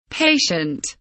patient kelimesinin anlamı, resimli anlatımı ve sesli okunuşu